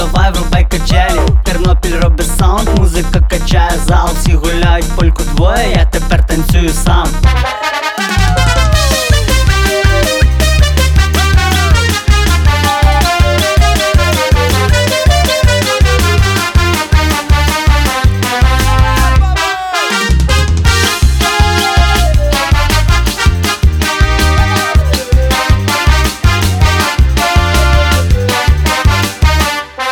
Жанр: Танцевальные / Украинские